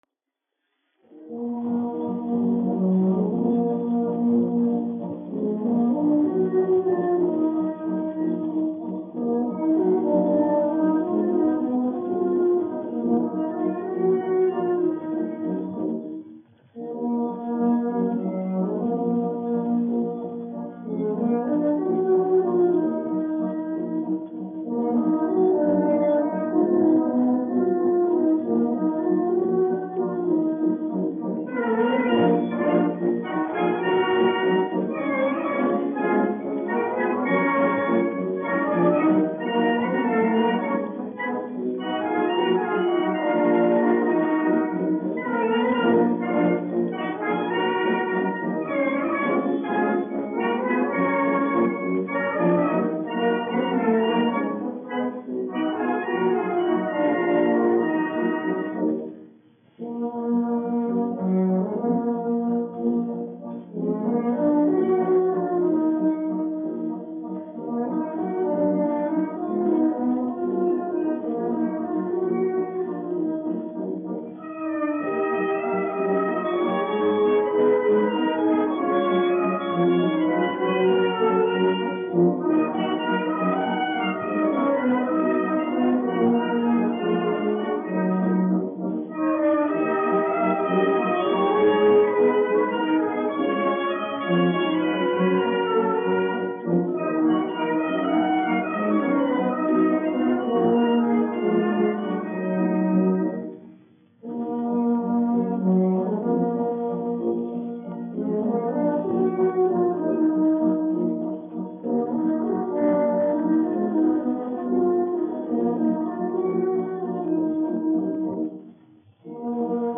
Latvijas 4. Valmieras kājnieku pulka orķestris, izpildītājs
1 skpl. : analogs, 78 apgr/min, mono ; 25 cm
Pūtēju orķestra mūzika
Valši
Skaņuplate